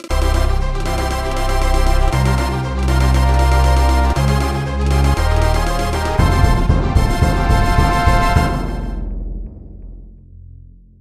Edited Clipped to 30 seconds and applied fade-out.